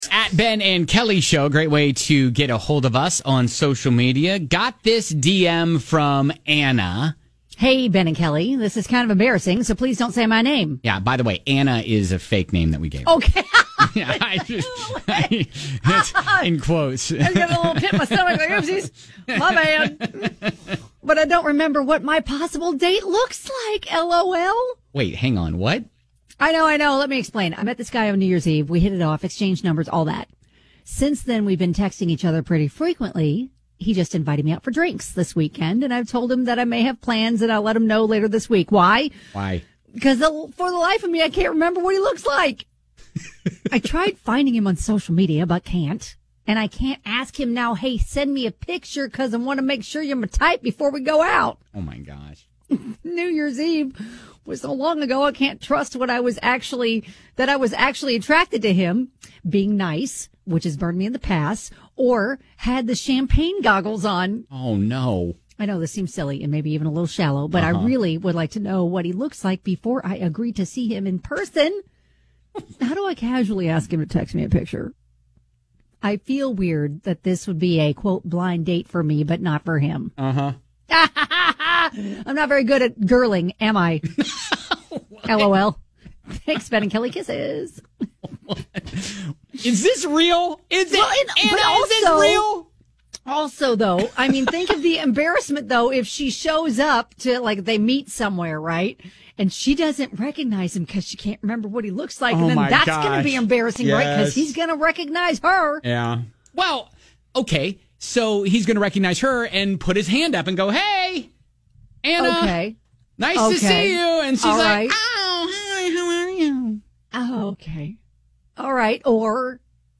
Personality radio